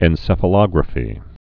(ĕn-sĕfə-lŏgrə-fē)